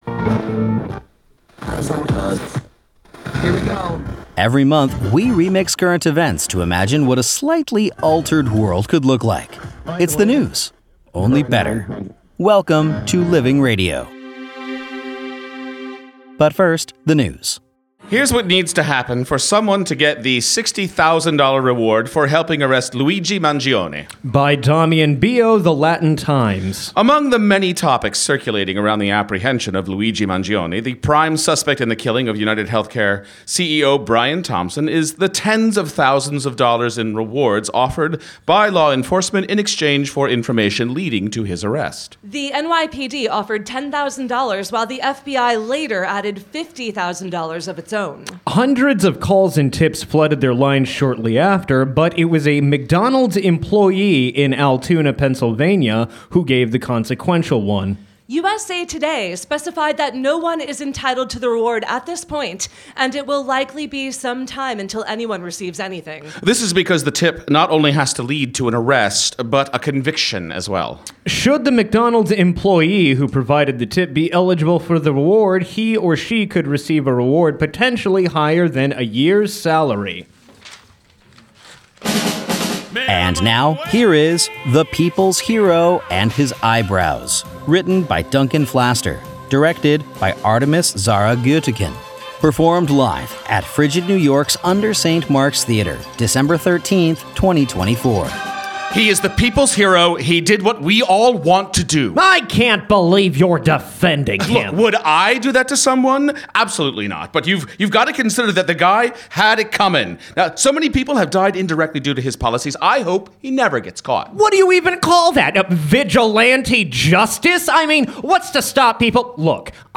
performed live at UNDER St. Mark’s Theater, December 13, 2024